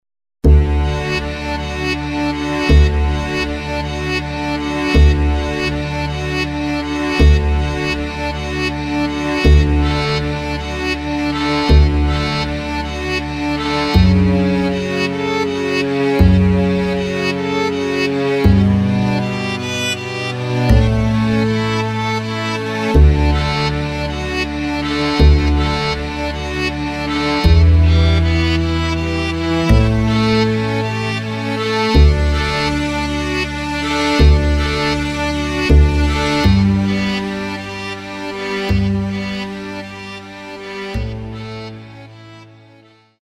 Takt:          3/4
Tempo:         80.00
Tonart:            D
Playback mp3 Mit Drums